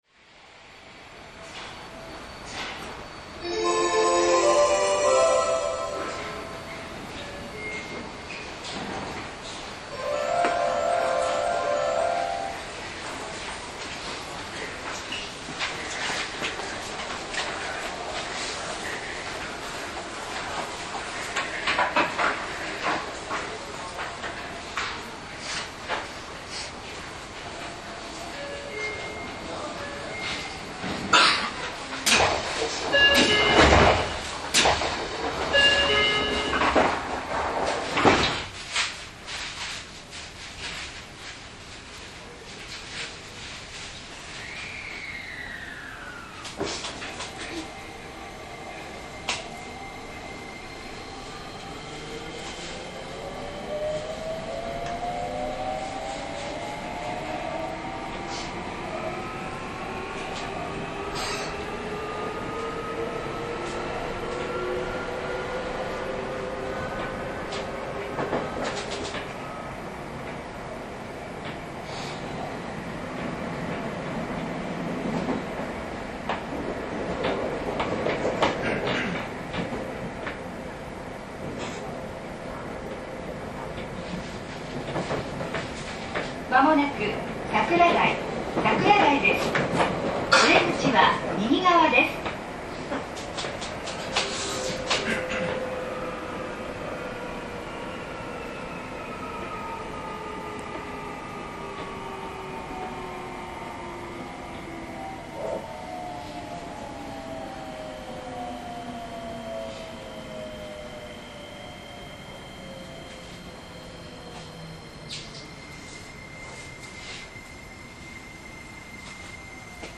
インバーター制御ですが、モーター音が静が過ぎです。
走行音